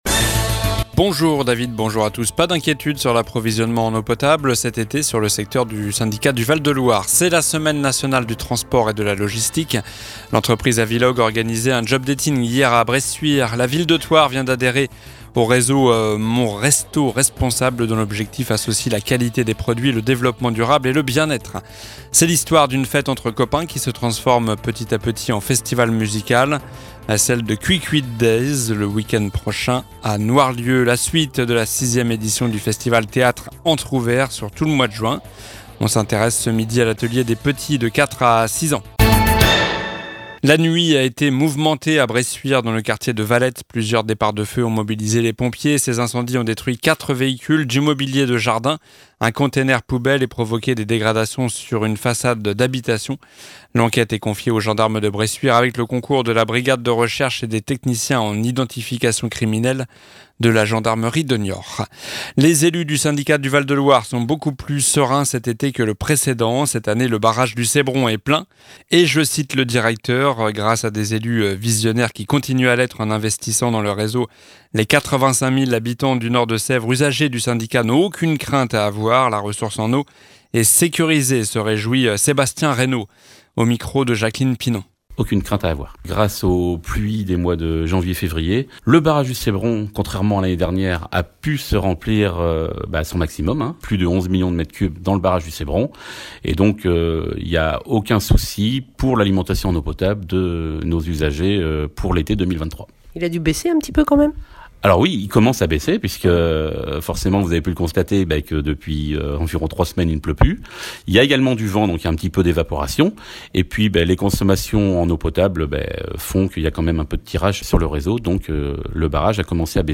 Journal du jeudi 08 juin (midi)